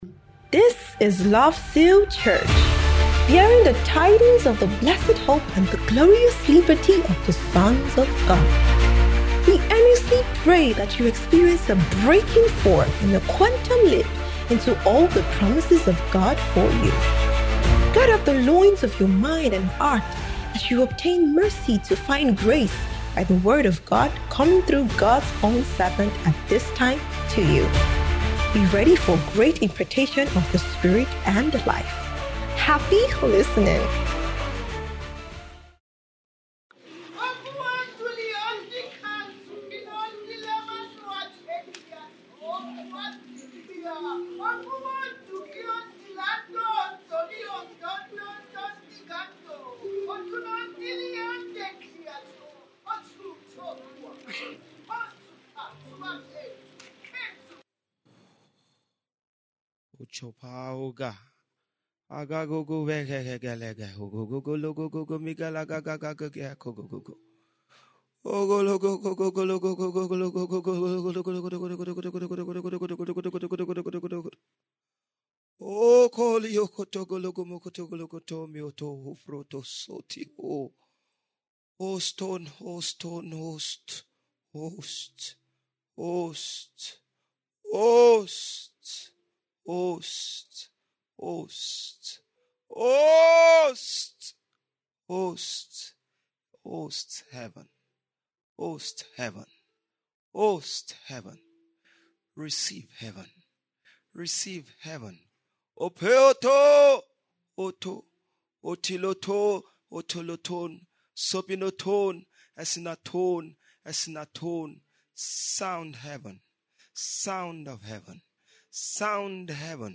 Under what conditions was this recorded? Spirit Life Reign 2025 - Grand Finale